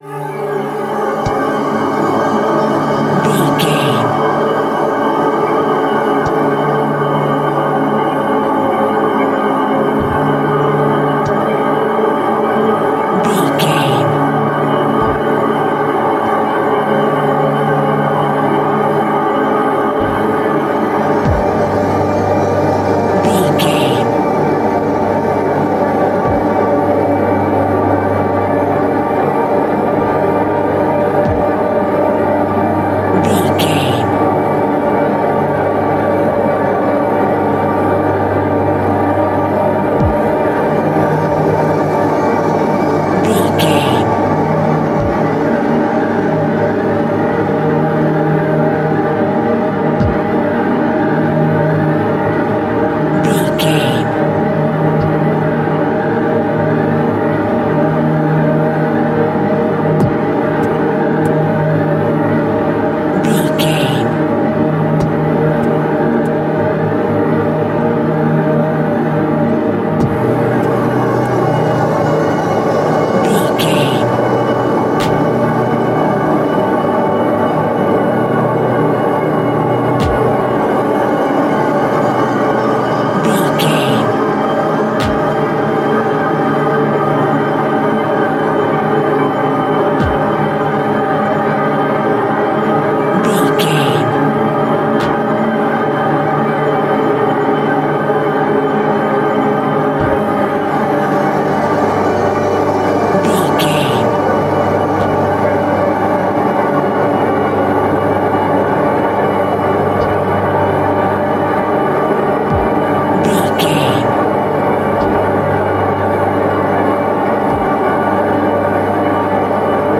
In-crescendo
Thriller
Aeolian/Minor
ominous
haunting
eerie
Horror Ambience
dark ambience
suspenseful
Synth Pads
Synth Ambience